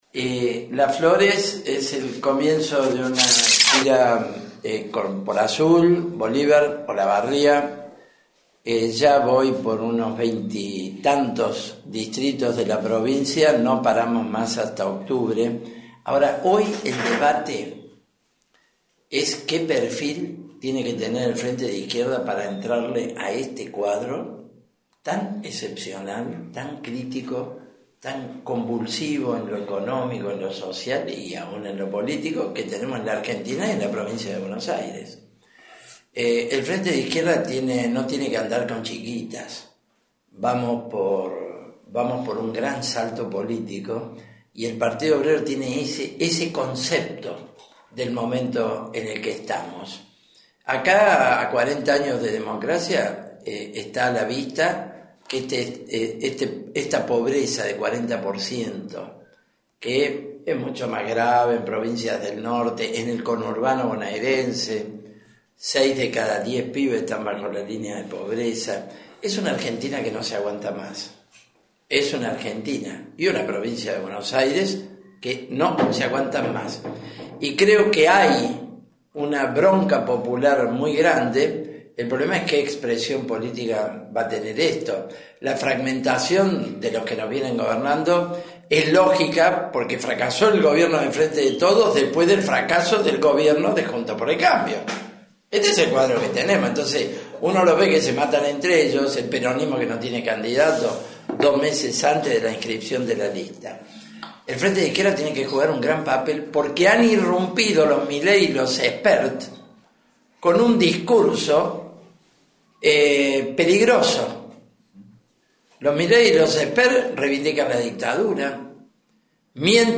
Pitrola, acompañado por referentes locales, ofreció una rueda de prensa en una confitería local.